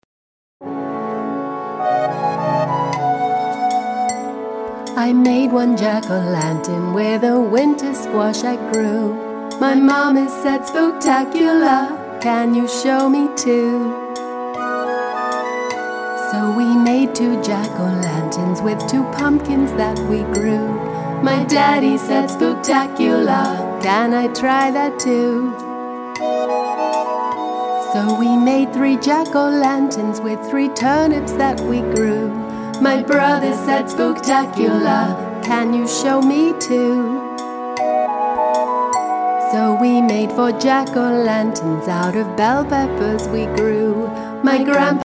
With three fun and silly songs for Halloween
not-too-scary spooky season EP